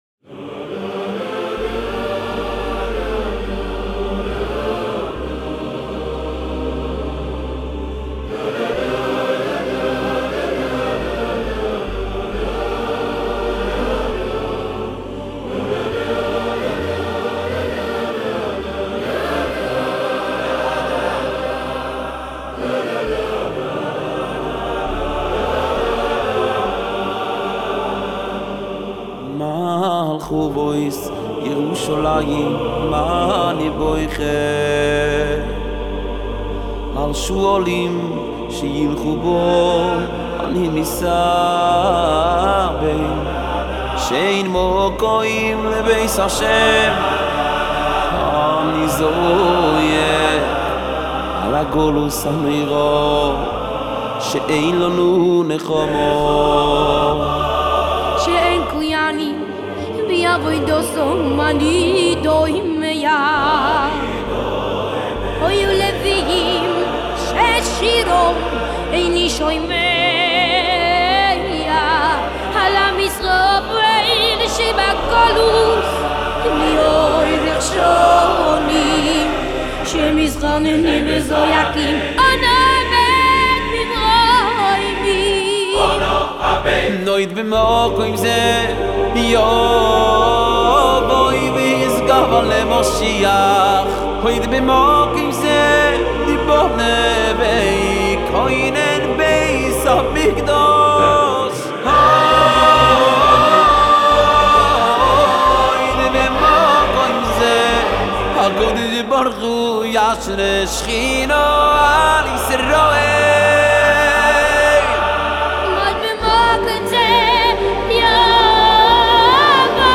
ברוח התקופה, הופק הקליפ בגרסה ווקאלית